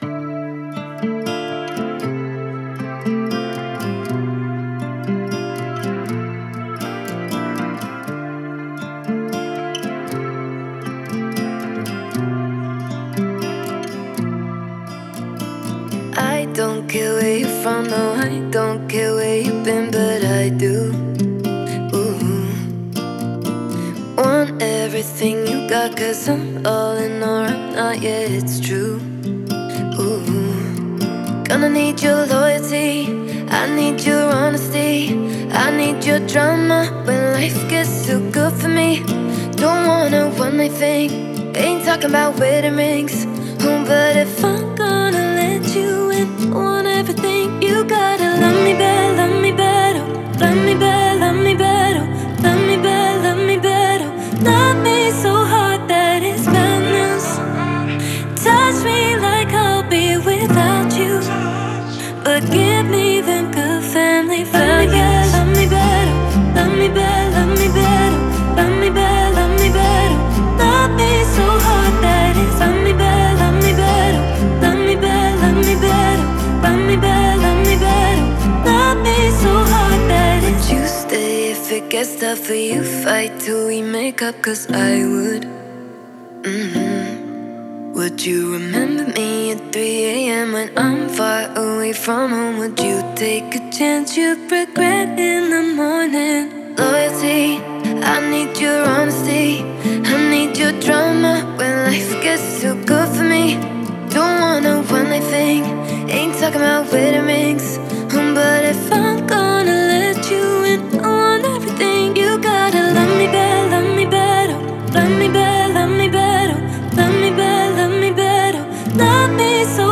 это трек в жанре поп с элементами фолка